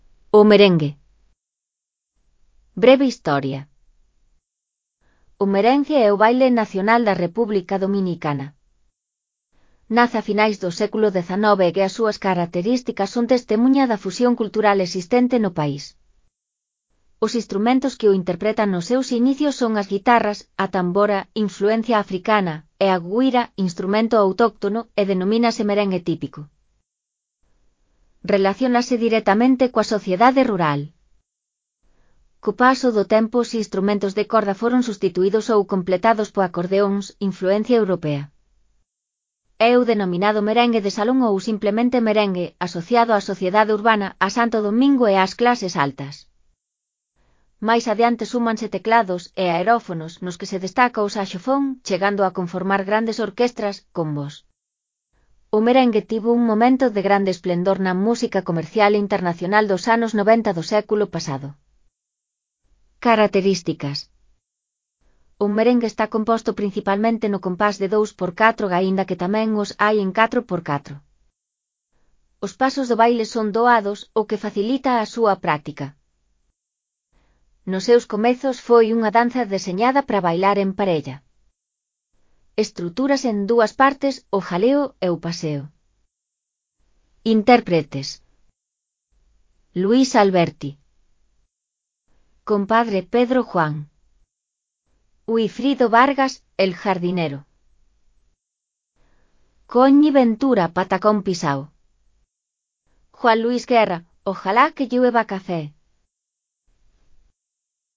3.3. Merengue
Os instrumentos que o interpretan nos seus inicios son as guitarras, a tambora (influencia africana) e a güira (instrumento autóctono) e denomínase “merengue típico”.
O merengue está composto principalmente no compás de 2/4 aínda que tamén os hai en 4/4.
AUD_MUD_6PRI_REA02_O_MERENGUE_V01.mp3